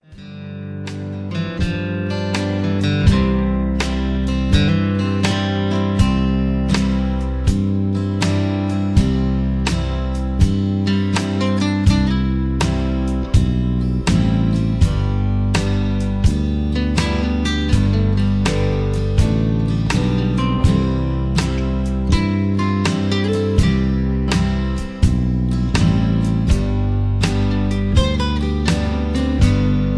karaoke, mp3 backing tracks, sound tracks
r and b, rock and roll, backing tracks